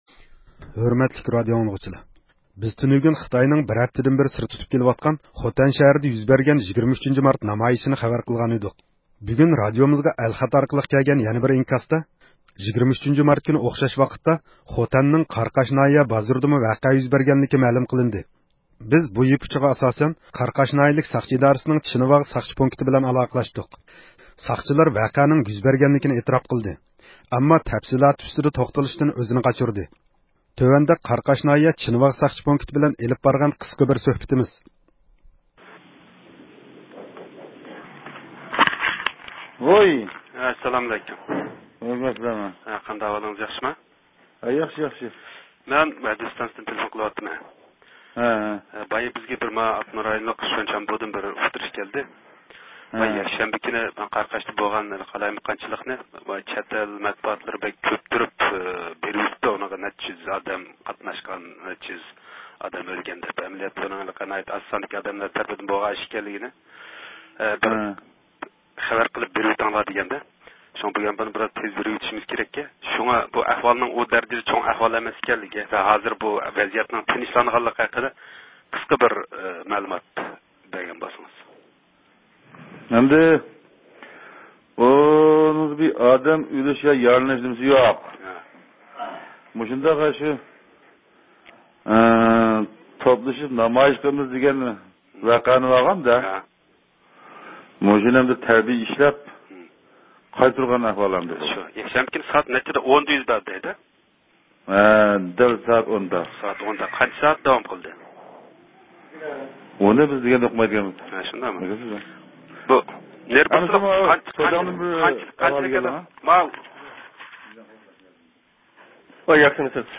بىز قاراقاش ناھىيىلىك ساقچى ئىدارىسىنىڭ چىنىۋاغ ساقچى پونكىتىغا تېلېفون قىلغىنىمىزدا، ساقچىلار ۋەقەنىڭ يۈز بەرگەنلىكىنى ئېتىراپ قىلدى .